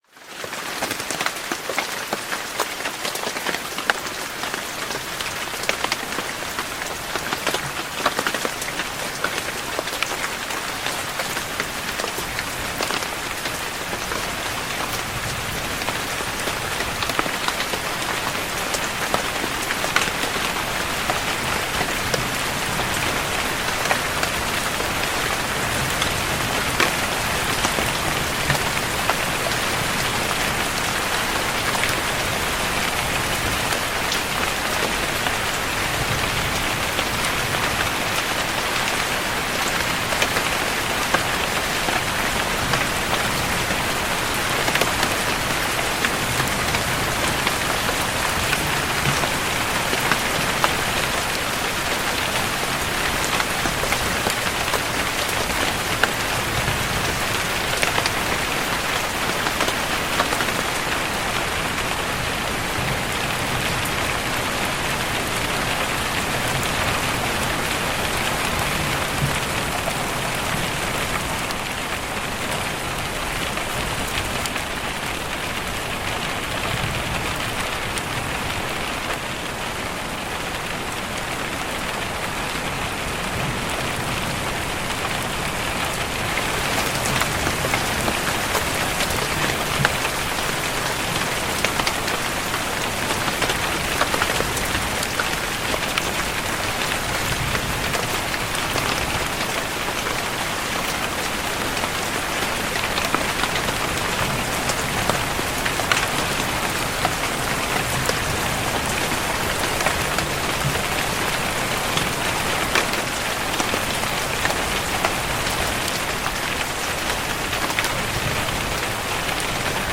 Lluvia y Trueno en un Refugio Natural para un Sueño sin Esfuerzo
Sonidos de Lluvia, Lluvia para Dormir, Lluvia Relajante, Lluvia Suave